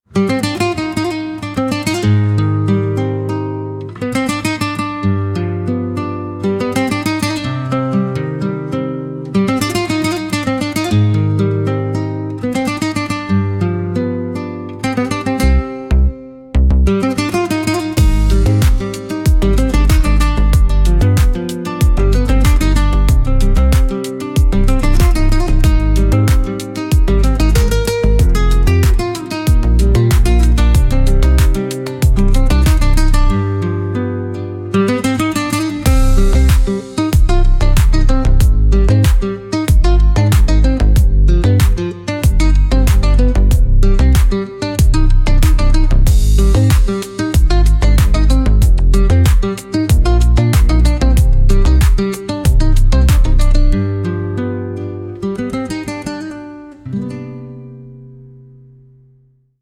94 BPM 1:00
Pop, World
Dramatic, Exciting, Elegant
94 BPM